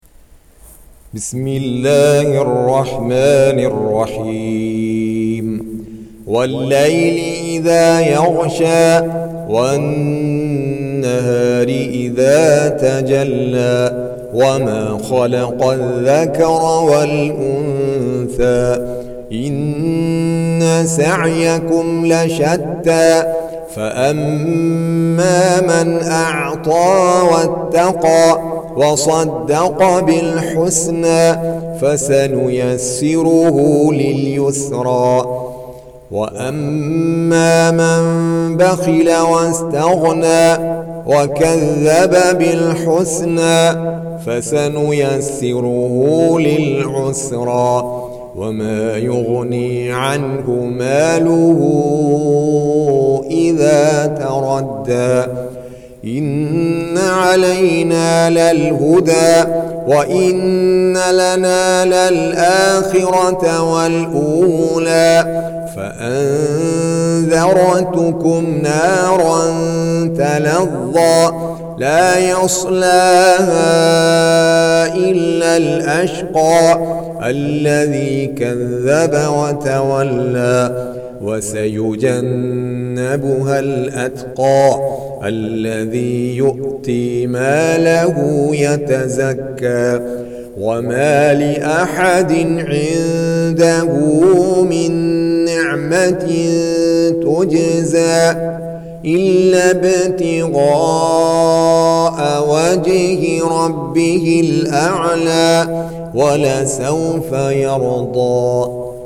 Surah Sequence تتابع السورة Download Surah حمّل السورة Reciting Murattalah Audio for 92. Surah Al-Lail سورة الليل N.B *Surah Includes Al-Basmalah Reciters Sequents تتابع التلاوات Reciters Repeats تكرار التلاوات